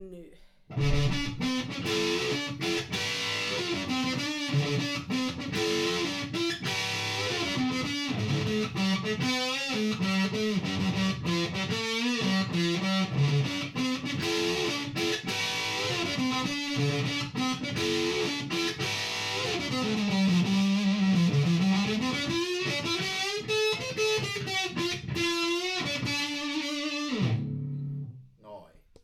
Marshall Supa Fuzz 1967 demopätkä
Nyt kuitenkin meillä asetelma oli sellainen, että kaiutinkaappi osoitti 180 astetta poispäin nauhoitinlaitteesta ja sen mikrofonista ja se siis poimi signaalin epäsuorasti huoneen akustiikan kautta. Tästä huolimatta lopputulos on melko kaiuton, kuiva ja raaka.
Loppuun vedin jotain pientä, vapaasti improvisoitua soolonpätkää.
Dissonantit "double stopit" riffissä ovat tarkoituksellisia, hieman venytystä ja vibraa, ei varmasti osu täsmälleen sävelen kohdalle, mutta pörisee minusta kohtuullisen mukavasti.
Pedaalin Filter eli fuzz-määrän asetuspotikka ei ole lähelläkään täysillä ja pedaalin Volume-potikka ei myöskään ole ihan täysillä.
Käytin demotallenteella vain tallamikkiä, mutta myös talla- ja kaulamikki yhdessä toimivat tässä riffissä oikein hyvin.
LISÄYS YLLÄ OLEVAAN BLOGIKIRJOITUKSEEN 2017-12-29: Sen verran vielä lisään, että demopätkässä Vox AC30 ei ole erityisen kovalla volumella.
marshall_supa_fuzz_1967_demo.mp3